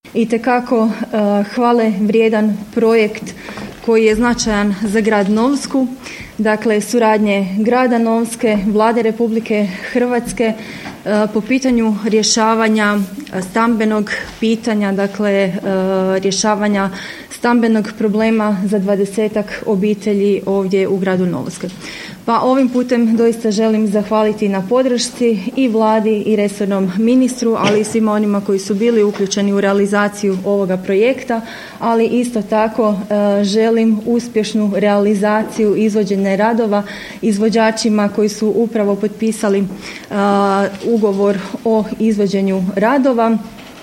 Gradonačelnica Novske Marija Kušmiš naglašava kako je ovo rezultat dobre međuresorne suradnje